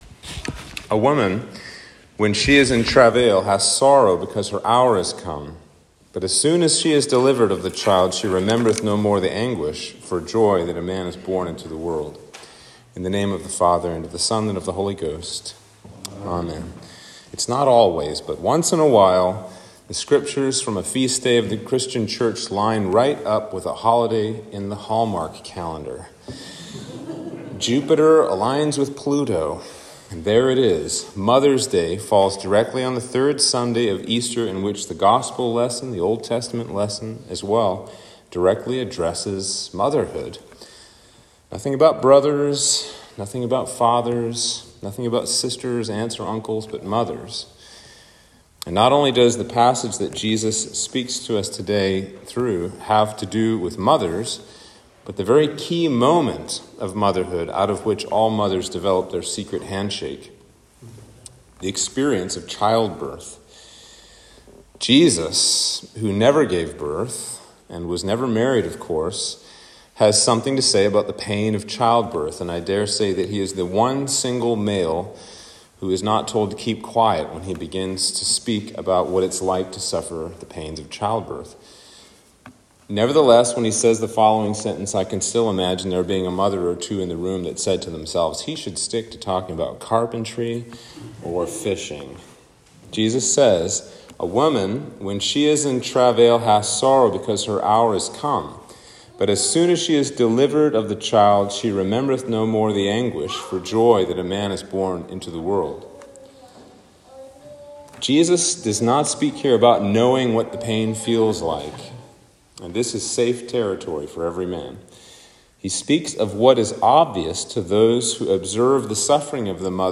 Sermon for Easter 3